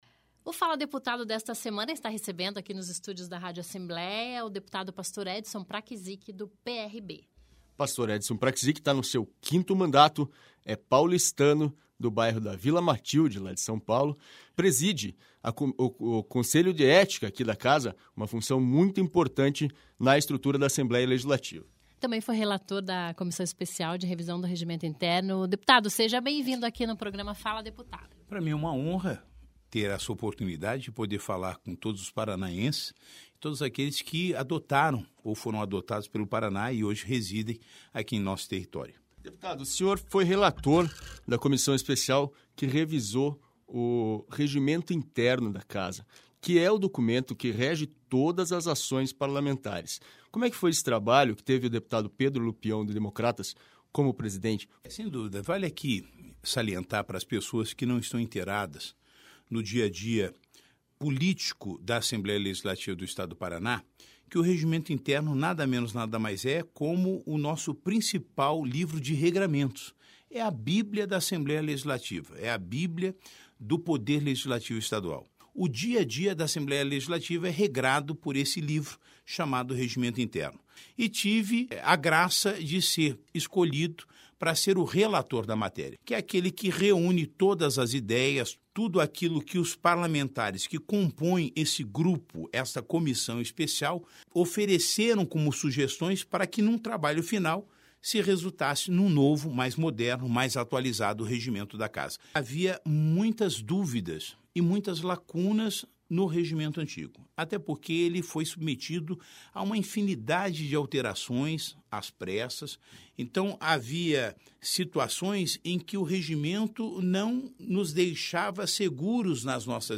Então ouça a entrevista na íntegra com Edson Praczyk a partir desta quinta-feira (3), em uma rádio aí pertinho de você ou aqui mesmo na página da Assembleia.